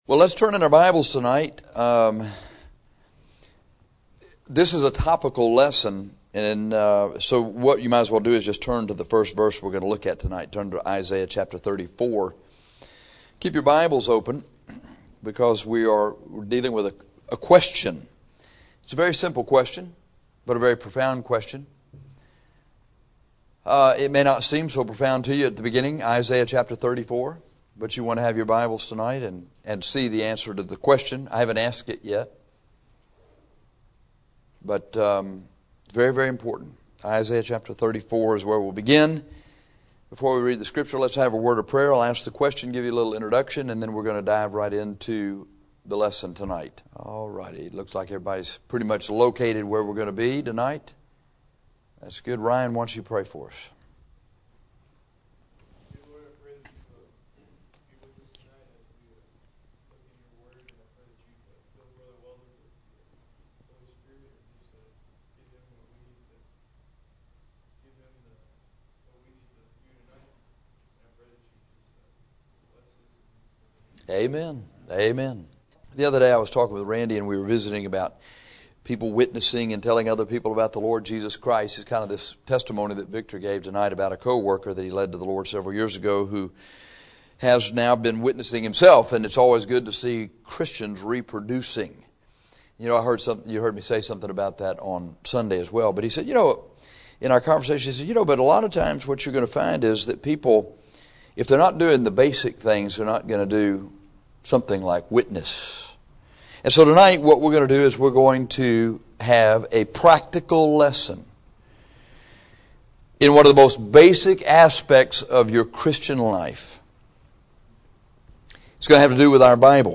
This is a practical lesson that deals with one of the most basic aspects of our Christian lives, our Bibles. This lesson is akin to a soldier going through basic training and learning about what to do with his weapon.